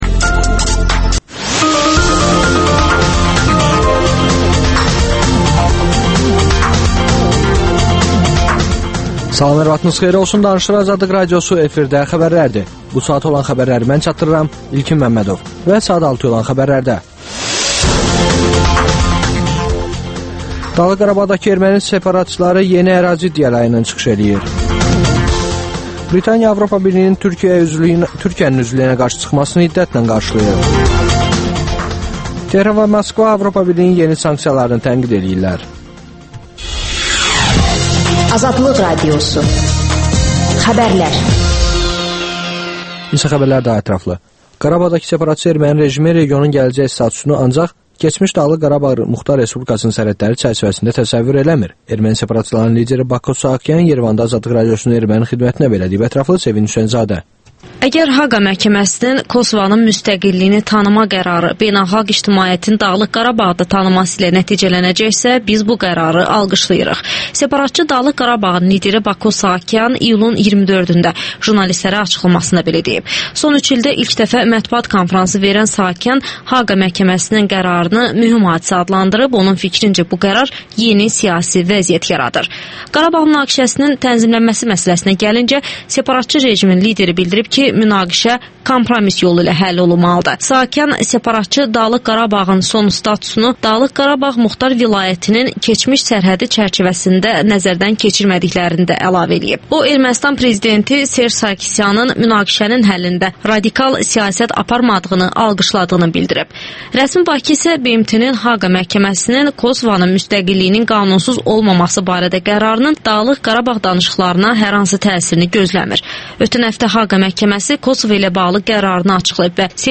«Parlament Saatı»nın qonağı deputat, Milli Məclisin Mədəniyyət Komitəsinin sədri Nizami Cəfərov dinləyicilərin suallarını cavablandırır